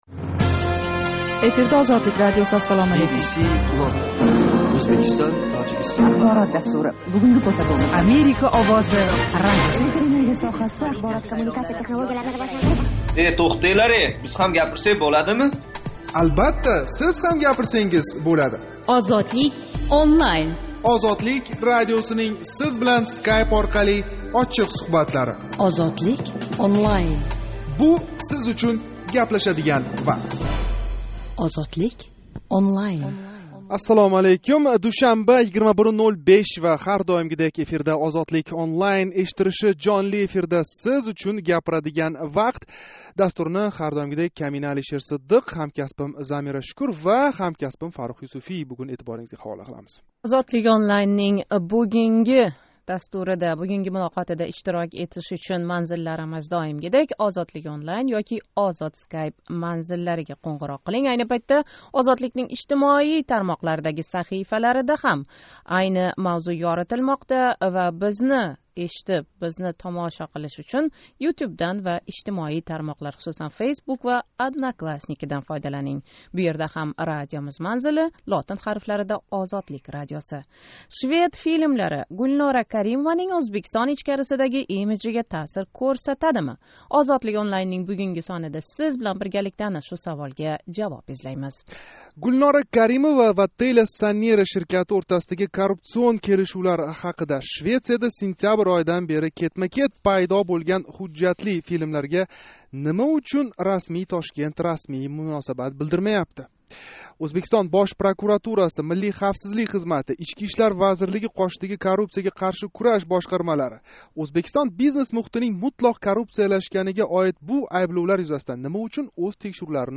Душанба¸ 27 май куни Тошкент вақти билан 21:05 да бошланган жонли интерактив мулоқотимизда Ўзбекистон президентлигига асосий ворис деб кўрилаëтган Гулнора Каримовага нисбатан Ғарбда қўйилаëтган ҳужжатли айбловлар ва унга ўзбекистонликлар муносабатини ўргандик.